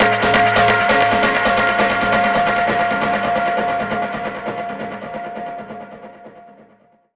Index of /musicradar/dub-percussion-samples/134bpm
DPFX_PercHit_E_134-04.wav